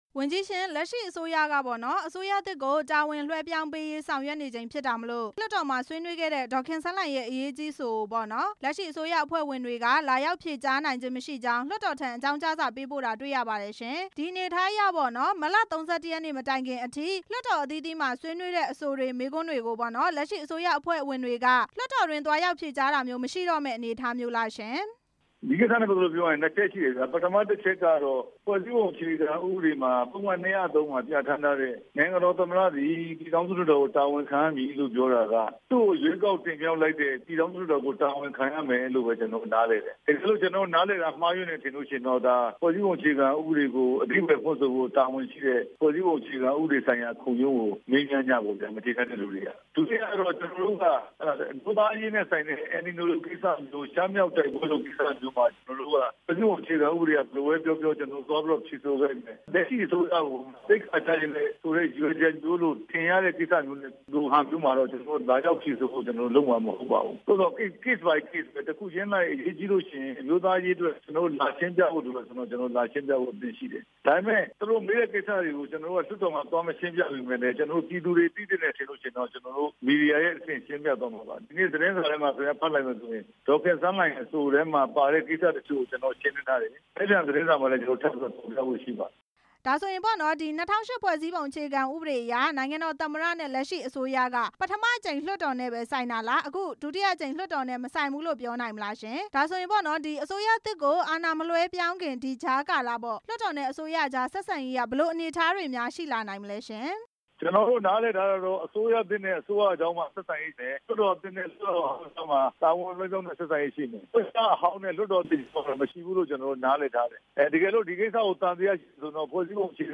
ပြန်ကြားရေးဝန်ကြီး ဦးရဲထွဋ်နဲ့ မေးမြန်းချက်